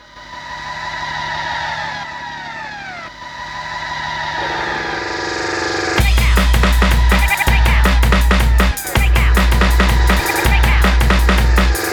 32 Hardcore-a.wav